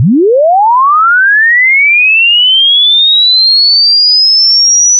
SoundtrackOptical/wavTones.com.unregistred.sweep_100Hz_6000Hz_-3dBFS_5s.wav at 5fb2fe7990cf22a384e2b681383ba12a2f4bac8e